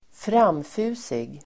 Uttal: [²fr'am:fu:sig]